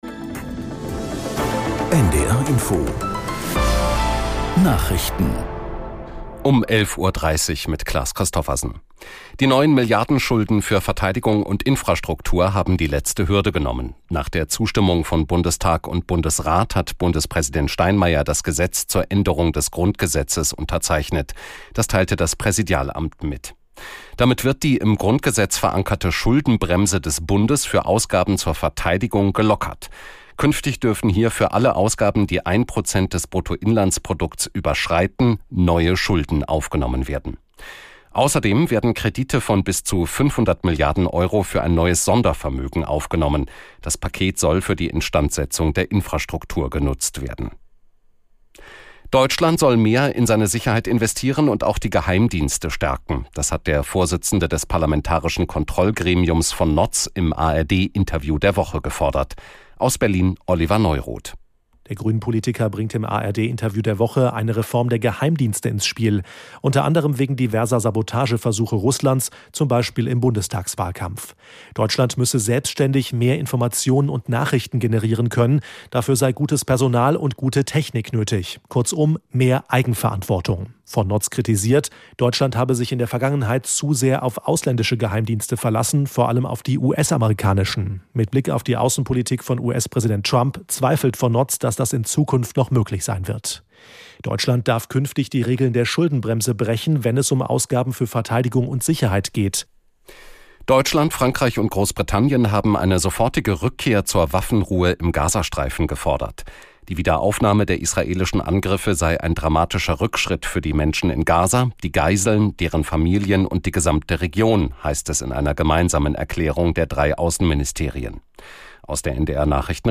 Nachrichten.